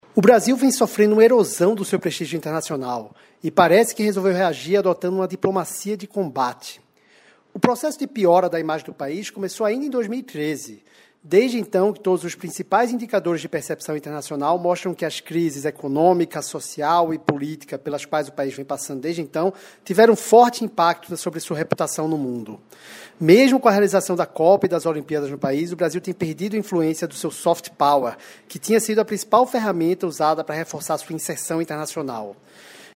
Categoria: Coluna